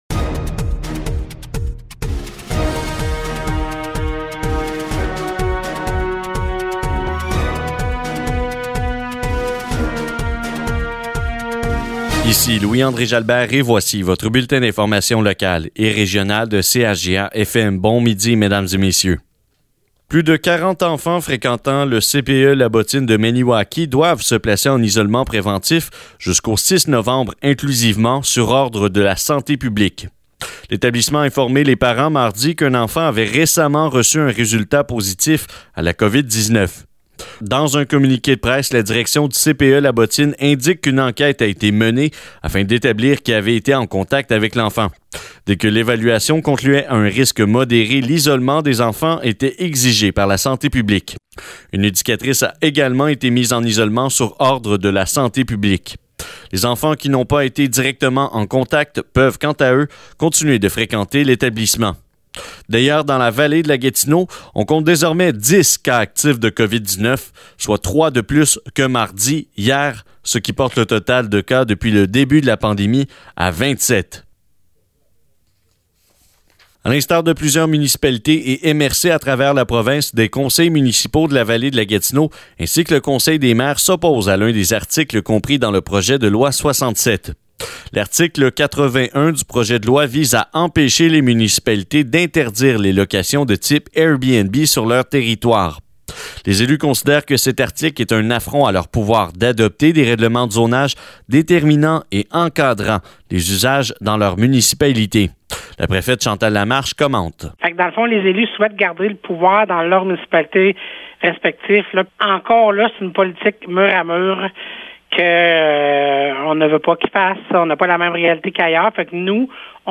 Nouvelles locales - 28 octobre 2020 - 12 h | CHGA